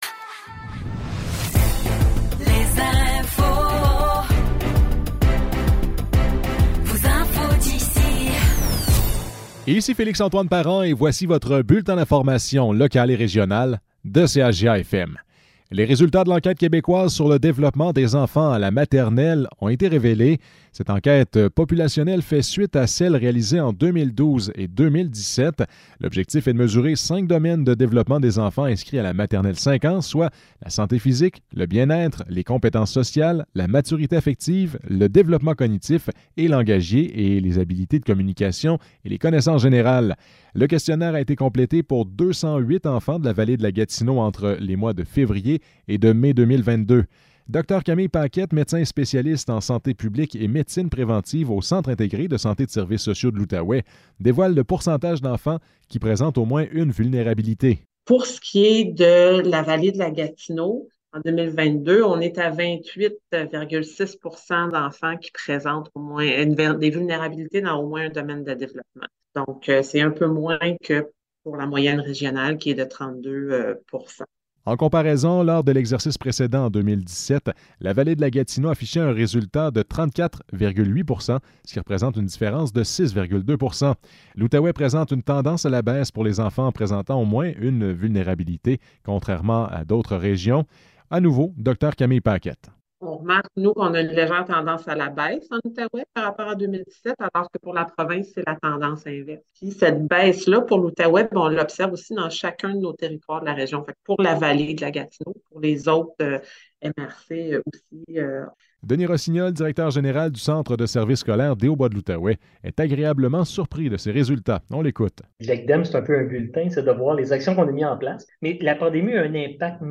Nouvelles locales - 16 octobre 2023 - 12 h